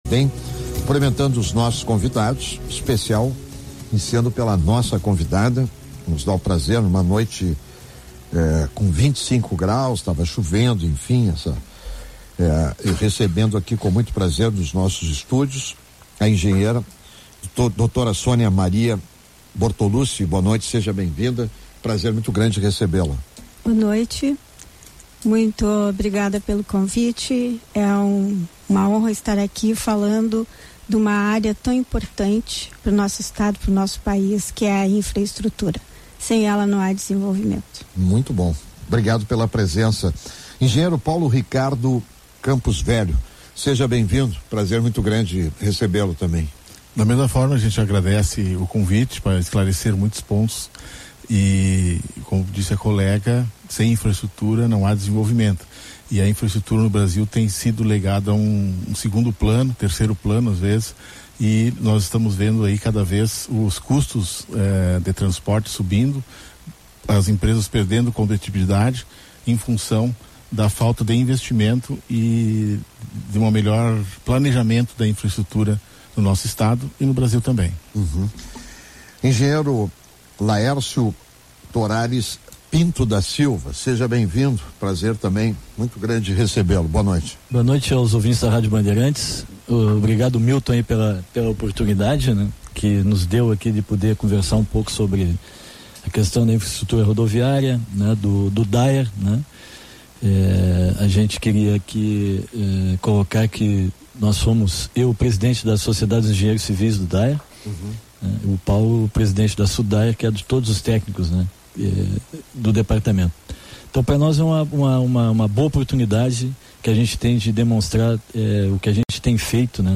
Parte 1 | Debate sobre estradas estaduais